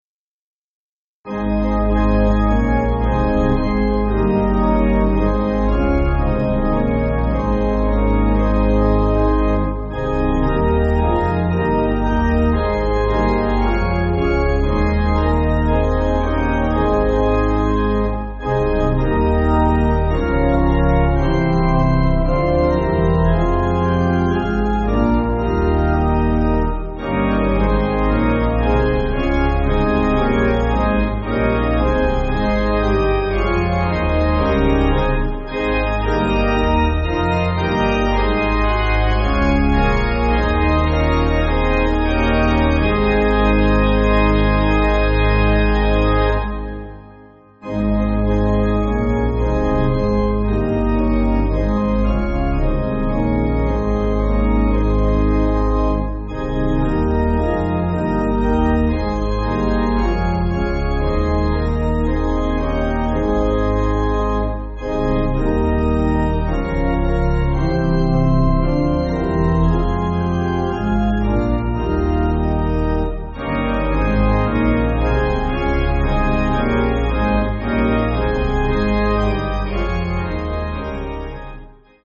Organ
(CM)   4/Ab